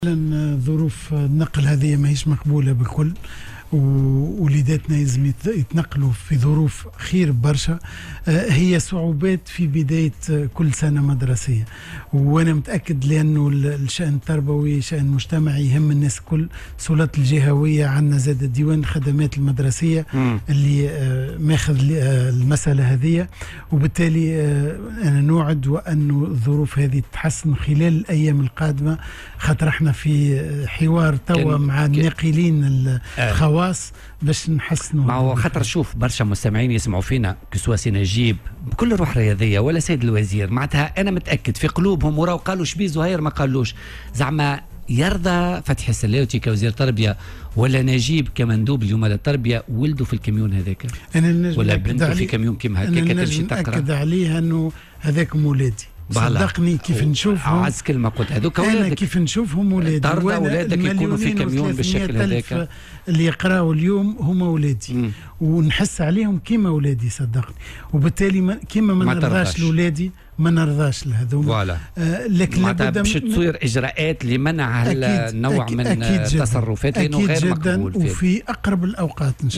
ووعد في مداخلة له اليوم على "الجوهرة أف أم" بتوفير ظروف أفضل لنقل التلاميذ، من خلال الحوار مع الناقلين الخواص خلال الأيام القليلة القادمة.